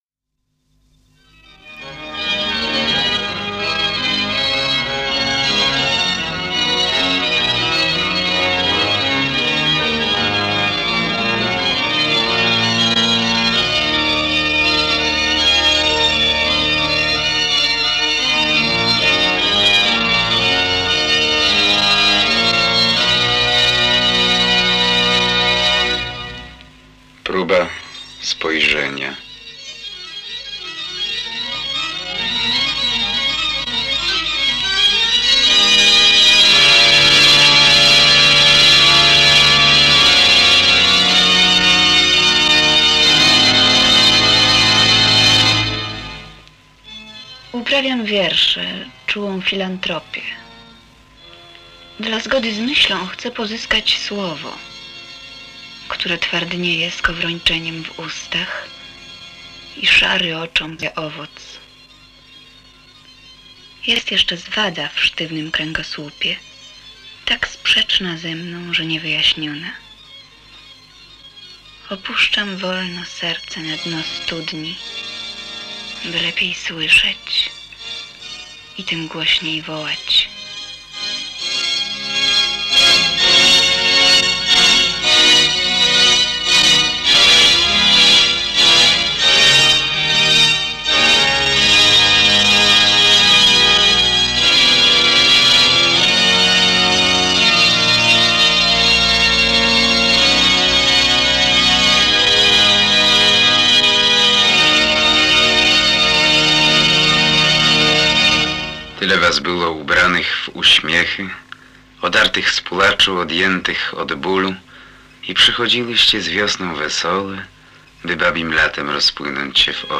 Audycja poetycka zawierająca wiersze W. Szymborskiej i Z. Herberta (II nagroda w Ogólnopolskim Konkursie z okazji X-lecia SAR)